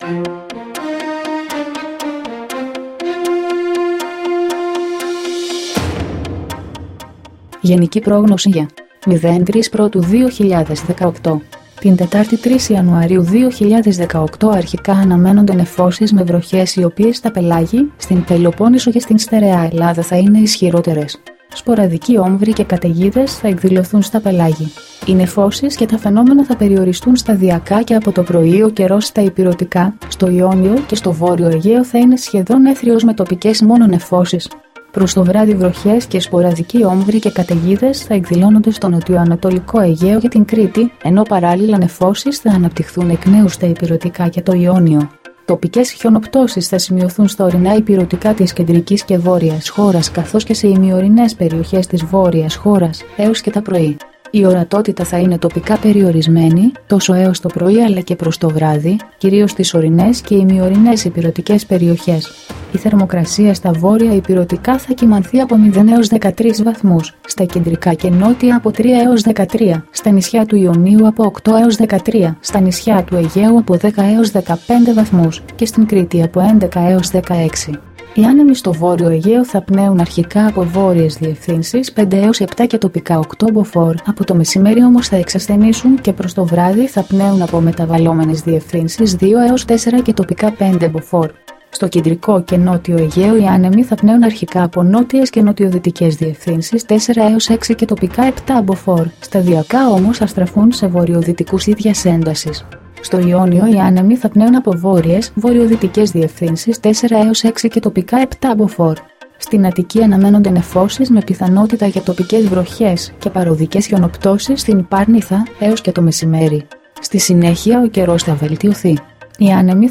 dailyforecast-60.mp3